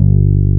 BAS.FRETG1-L.wav